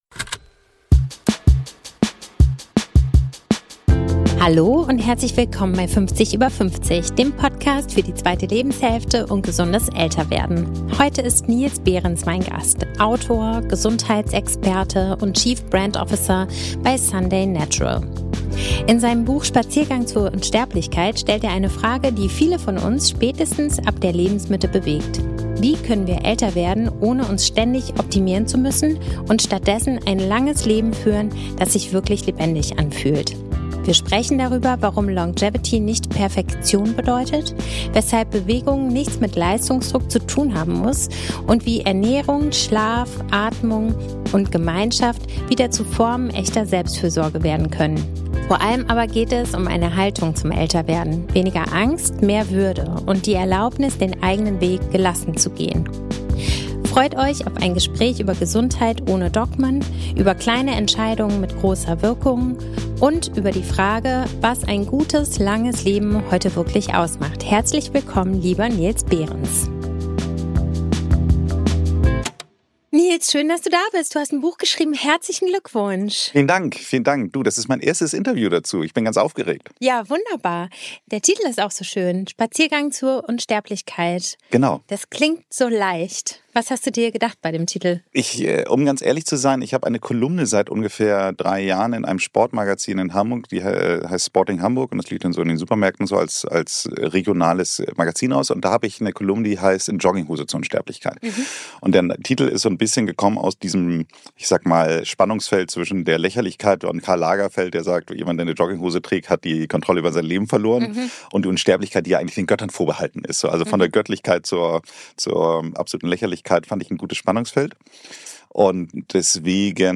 Wir sprechen darüber, warum Longevity nicht Perfektion bedeutet, weshalb Bewegung nichts mit Leistungsdruck zu tun haben muss und wie Ernährung, Schlaf, Atmung und Gemeinschaft wieder zu Formen echter Selbstfürsorge werden können. Vor allem aber geht es um eine Haltung zum Älterwerden: weniger Angst, mehr Würde und die Erlaubnis, den eigenen Weg gelassen zu gehen. Freut euch auf ein Gespräch über Gesundheit ohne Dogmen, über kleine Entscheidungen mit großer Wirkung und über die Frage, was ein gutes, langes Leben heute wirklich ausmacht.